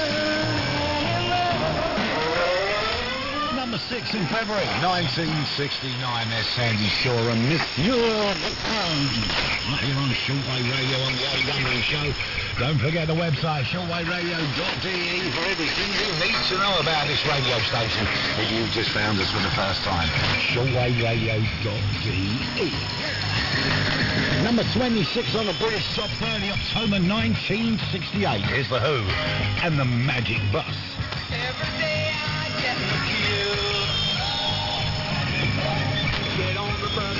Музыкальный канал на КВ